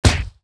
punch_impact_02.wav